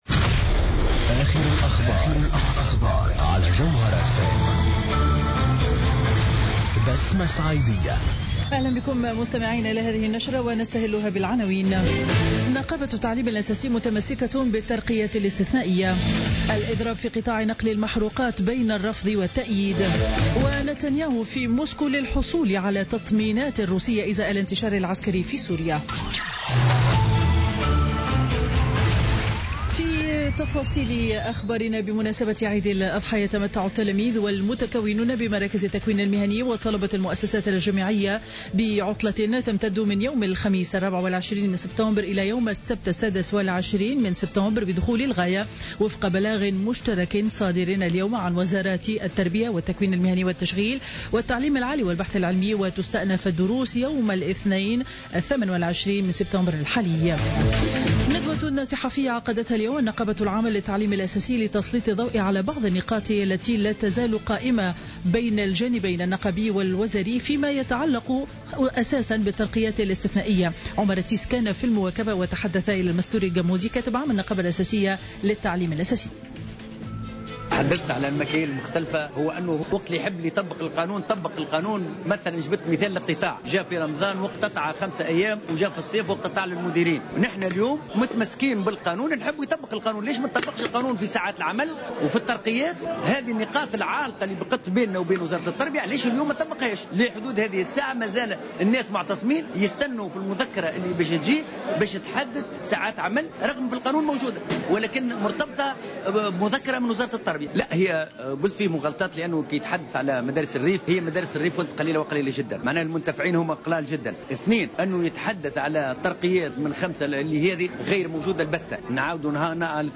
نشرة أخبار منتصف النهار ليوم الاثنين 21 سبتمبر 2015